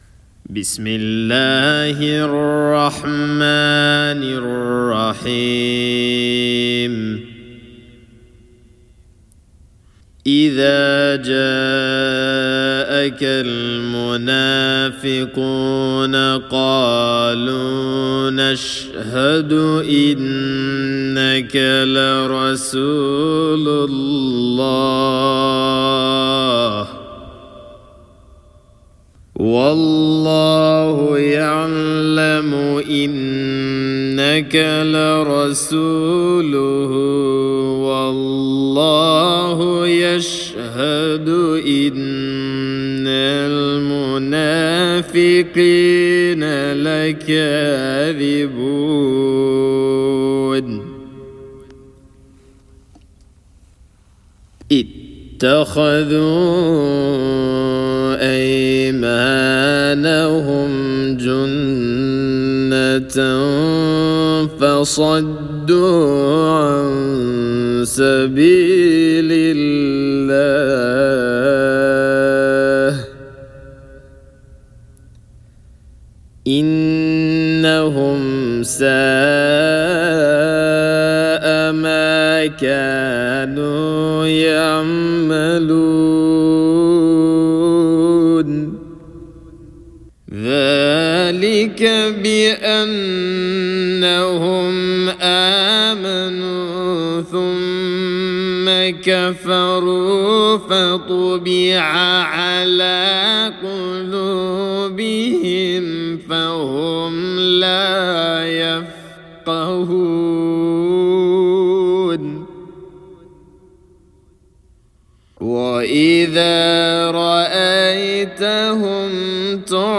English: Ch. 63 in mujawwad
Chapter_63,_Al-Munafiqun_(Mujawwad)_-_Recitation_of_the_Holy_Qur'an.mp3